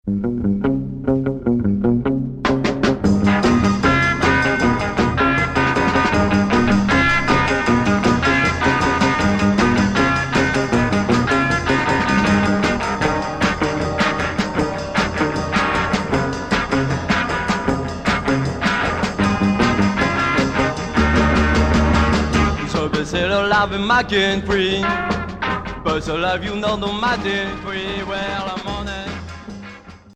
R'n'r